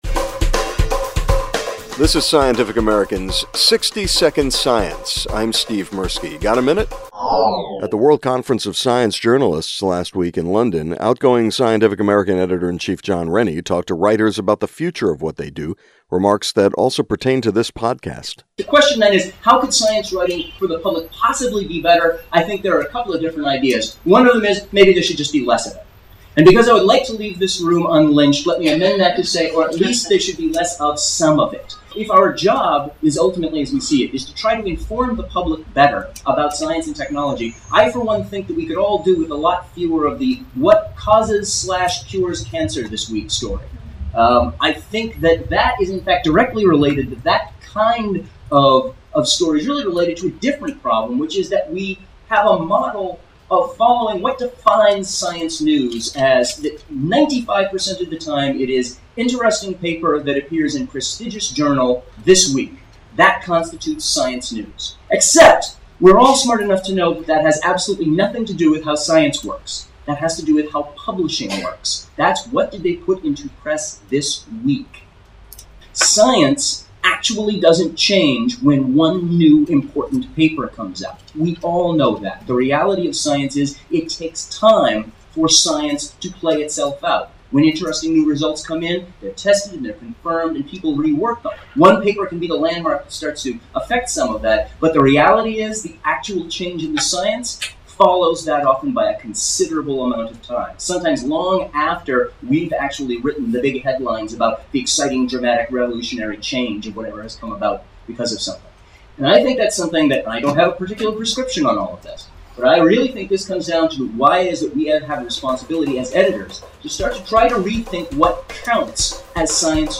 At the World Conference of Science Journalists last week in London